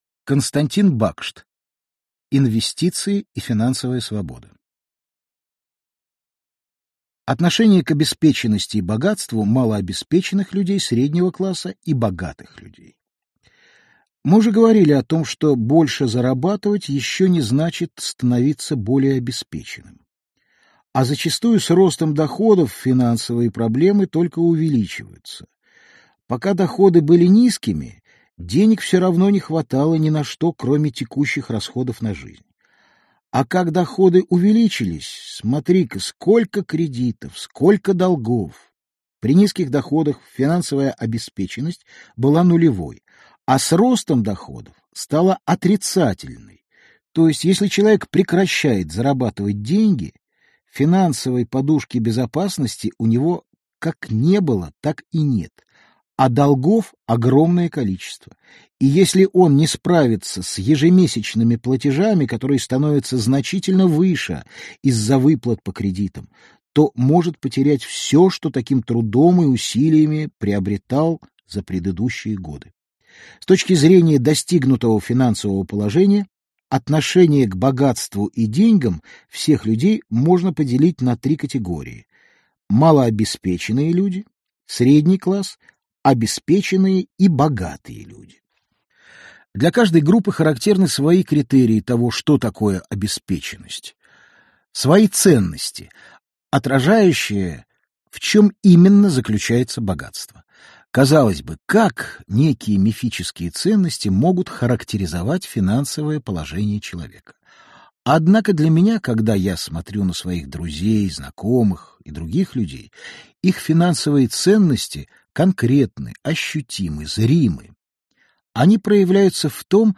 Аудиокнига Инвестиции и финансовая свобода | Библиотека аудиокниг